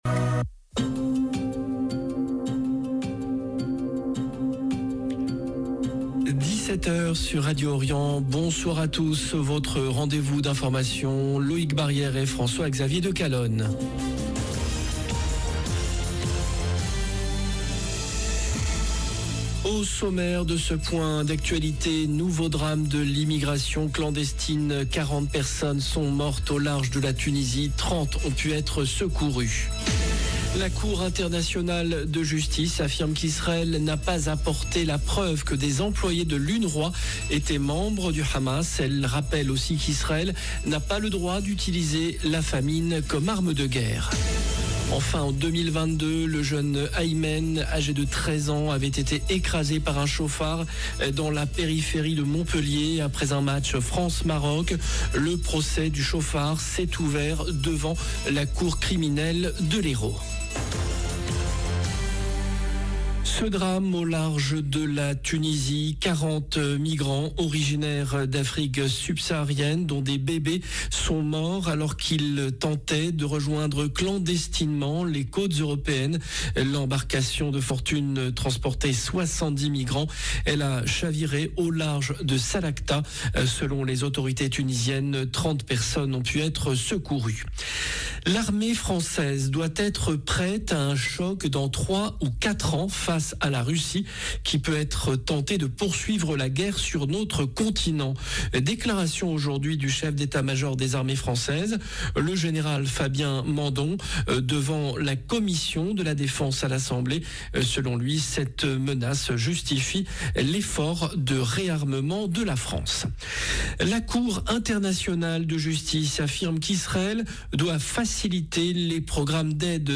Journal de 17H du 22 octobre 2025